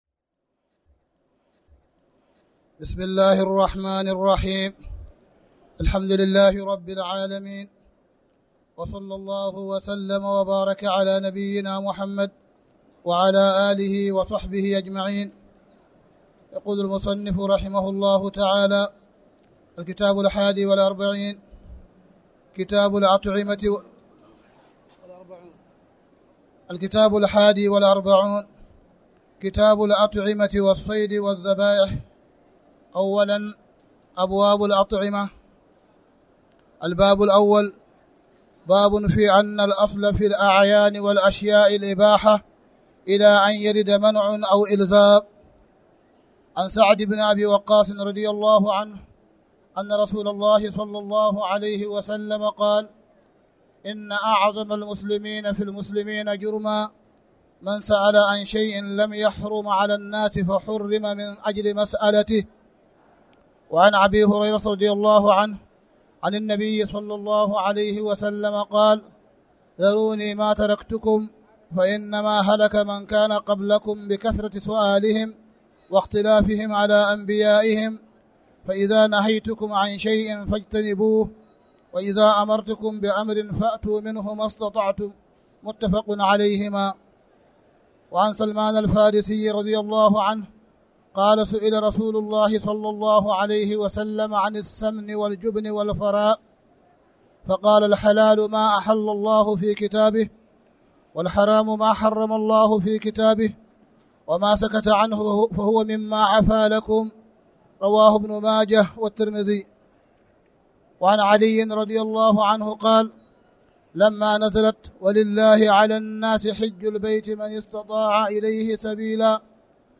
تاريخ النشر ٢٤ ذو القعدة ١٤٣٨ هـ المكان: المسجد الحرام الشيخ: معالي الشيخ أ.د. صالح بن عبدالله بن حميد معالي الشيخ أ.د. صالح بن عبدالله بن حميد كتاب الأطعمة والصيد والذبائح